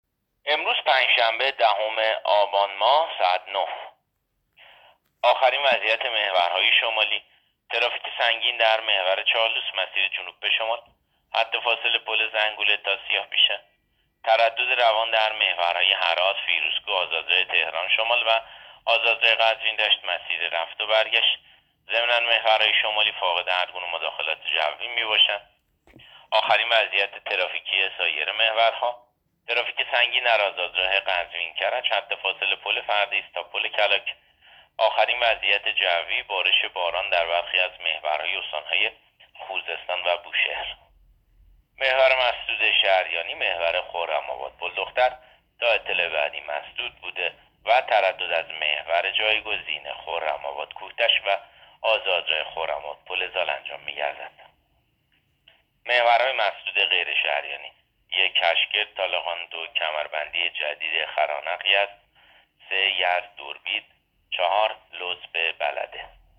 گزارش رادیو اینترنتی از آخرین وضعیت ترافیکی جاده‌ها تا ساعت ۹ دهم آبان؛